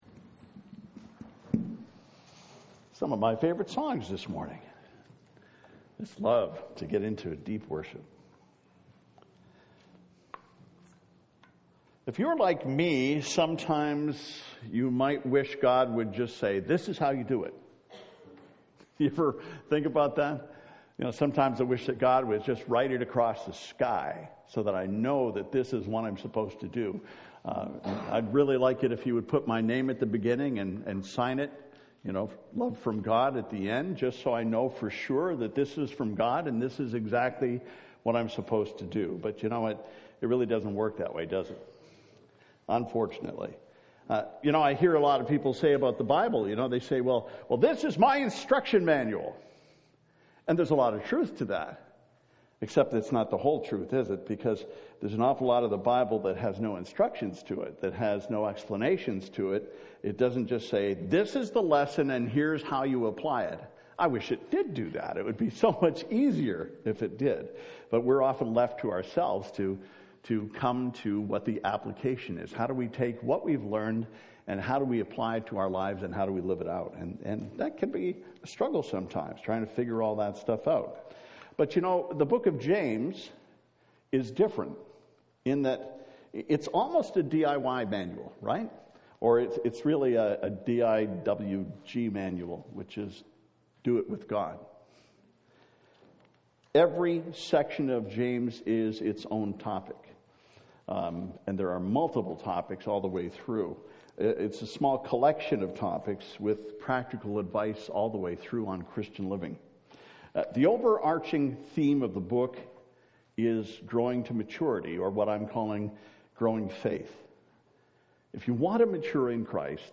February « 2019 « FABIC Sermons